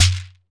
PRC SHEKER00.wav